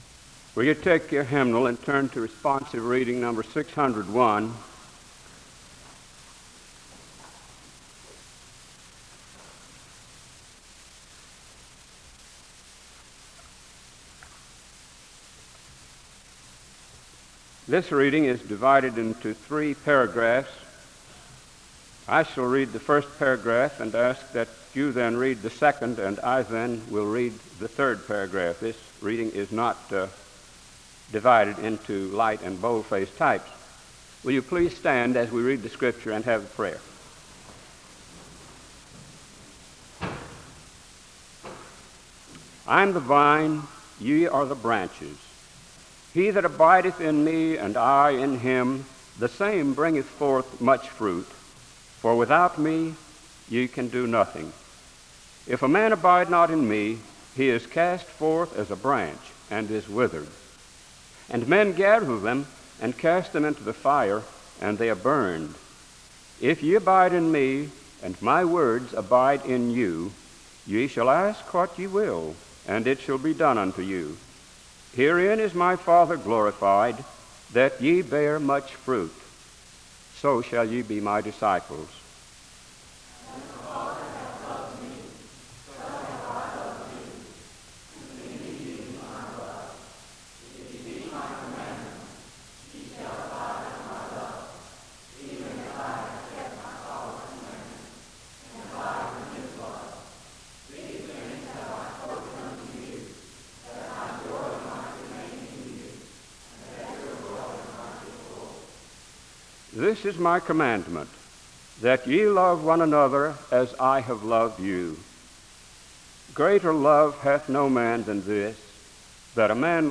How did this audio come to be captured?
The service begins by a reading from a Hymnal (0:00-2:07), which is followed by a prayer (2:08-3:14). The service is concluded in prayer (39:15-41:43).